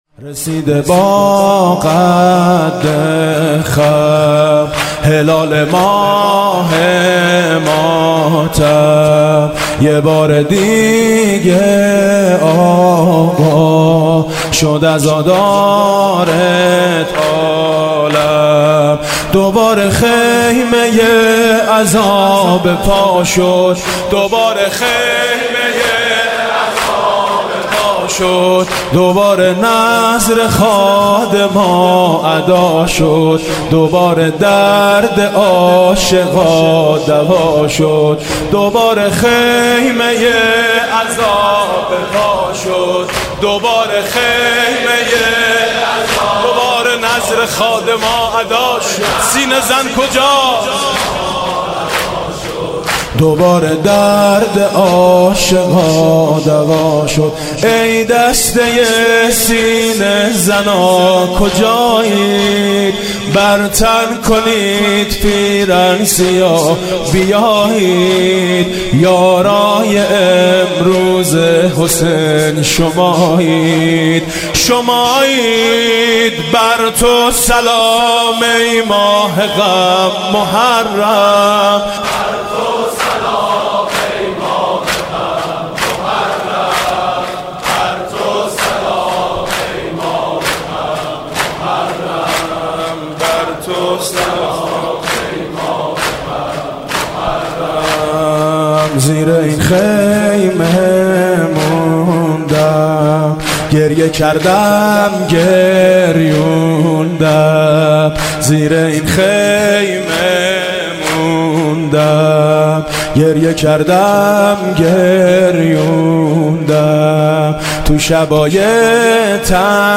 صوت مراسم شب اول محرم 1438 هیئت میثاق با شهدا ذیلاً می‌آید: